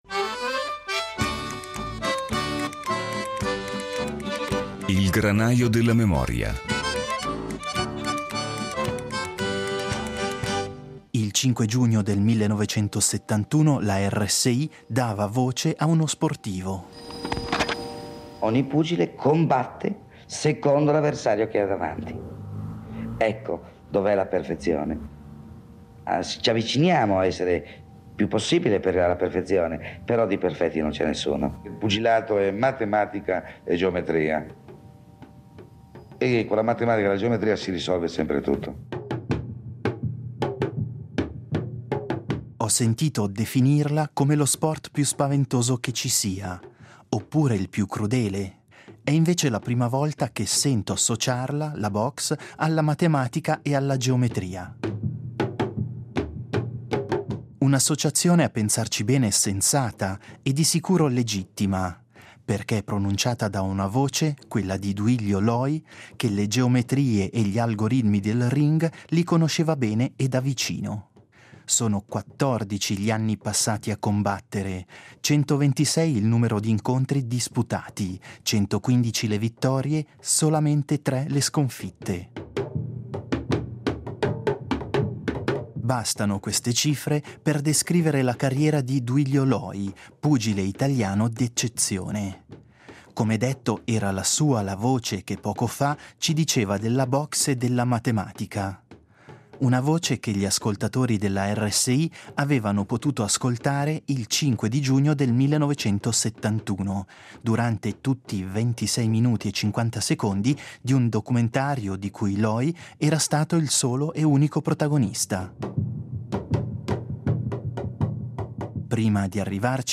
A qualche anno di distanza, nel 1971, la RSI incontra Duilio Loi durante un’emissione dedicata ai grandi ‘ex’ dello sport. Da quell’incontro tra il microfono e l’ex campione il “Granaio della memoria” ha ricavato i frammenti sonori che comporranno l’abituale percorso d’archivio radiofonico.